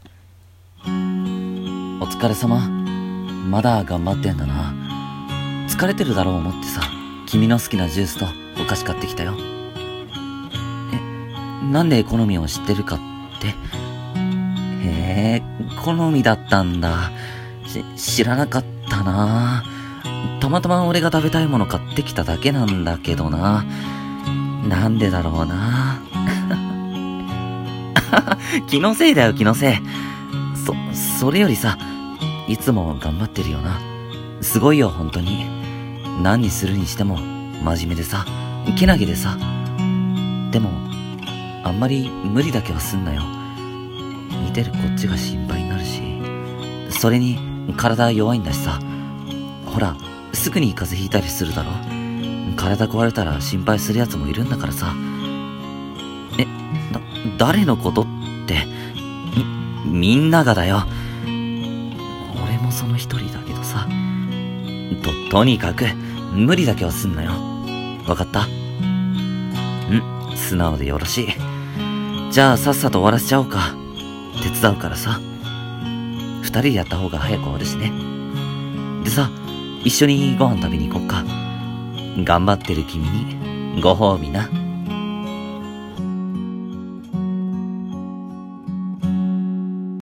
1人声劇